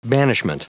Prononciation du mot banishment en anglais (fichier audio)
Prononciation du mot : banishment